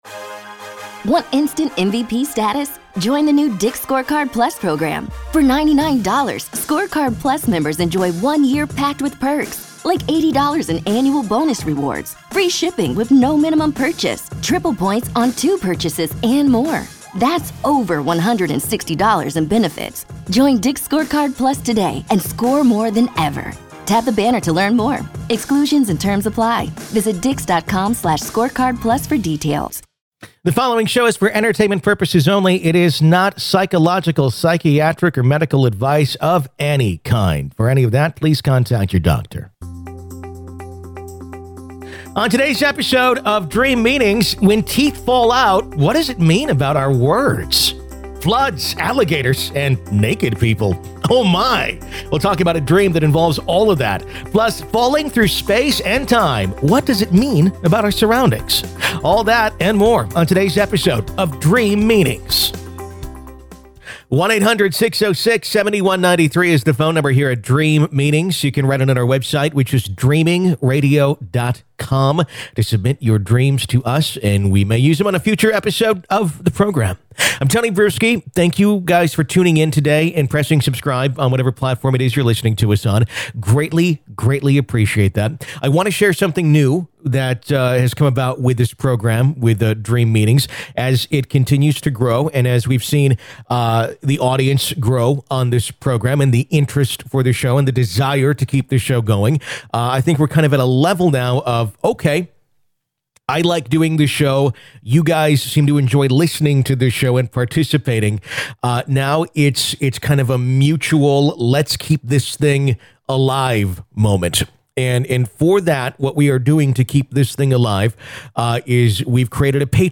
On Dream Meanings, we take your calls and read your letters about your dreams and give you our opinion and dream interpretation.